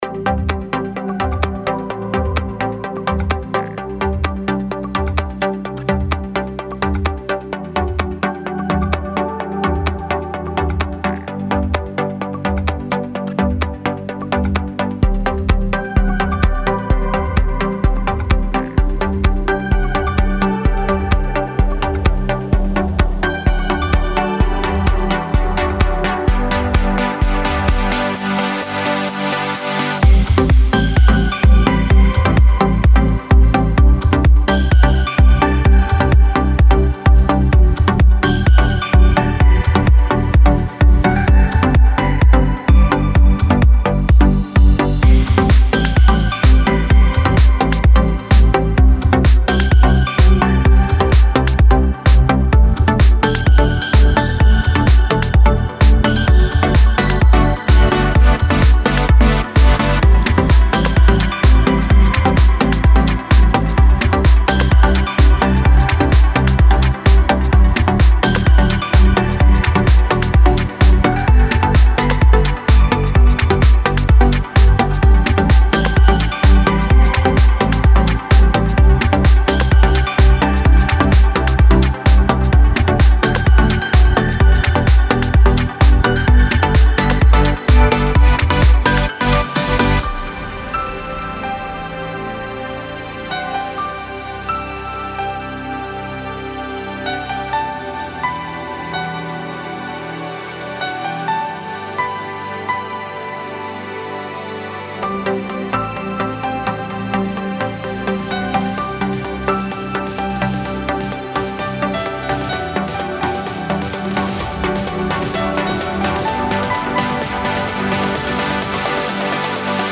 Зацените сведение A2 ( Progressive, House, Deep)